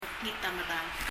mekngit a medal [mɛkŋi(t) ə məðəl]